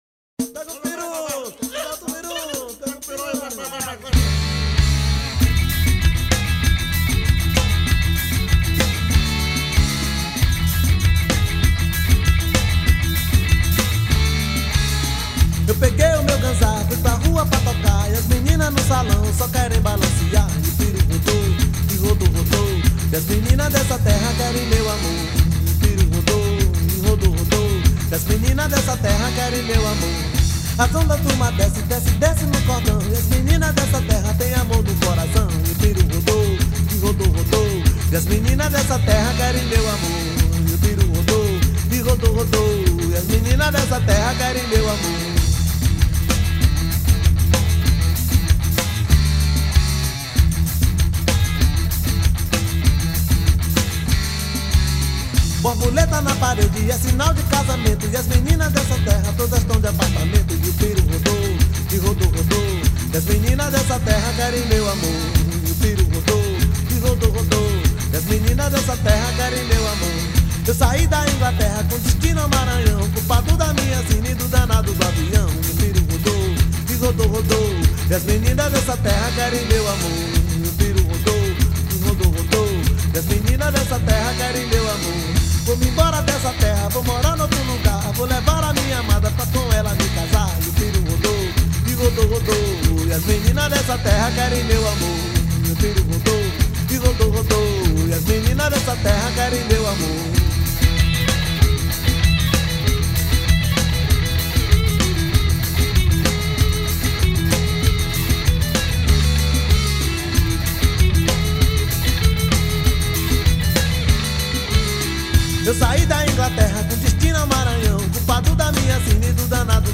2619   02:47:00   Faixa: 10    Reggae